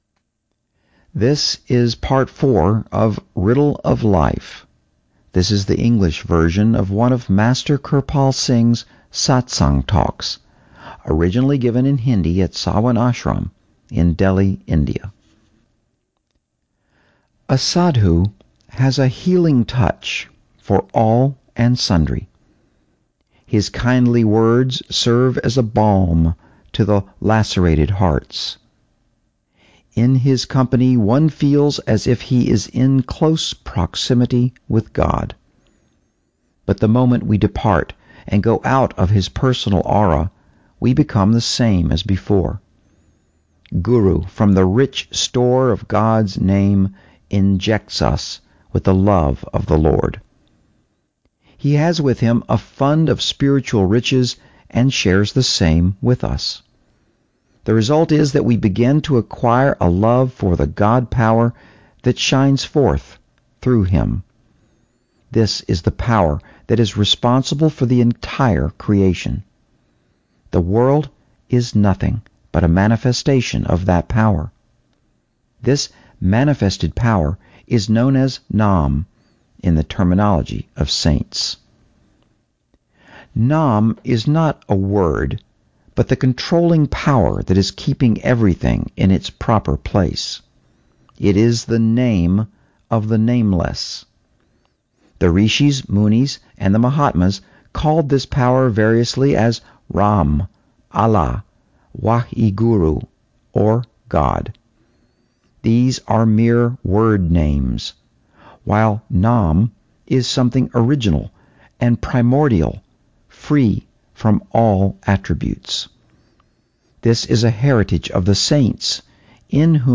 Sant Kirpal Singh - audio books